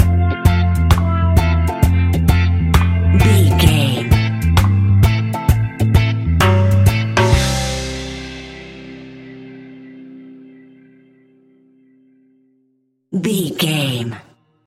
Classic reggae music with that skank bounce reggae feeling.
Ionian/Major
laid back
chilled
off beat
drums
skank guitar
hammond organ
percussion
horns